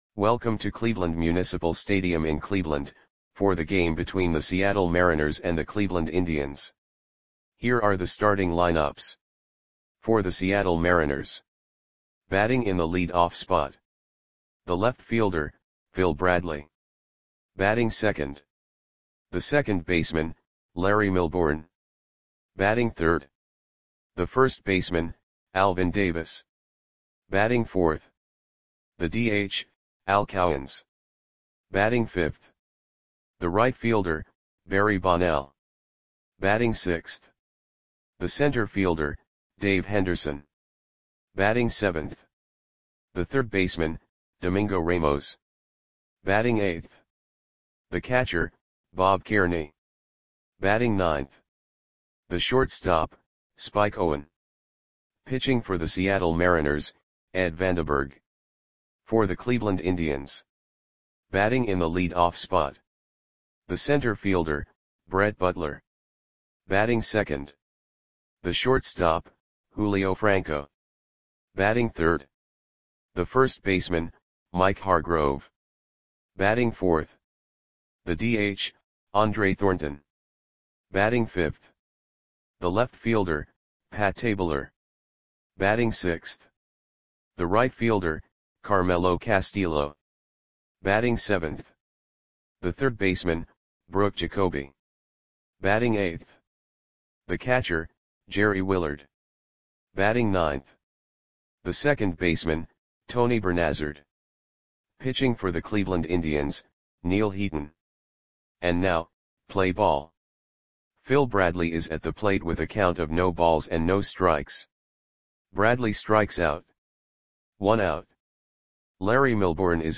Audio Play-by-Play for Cleveland Indians on June 10, 1984
Click the button below to listen to the audio play-by-play.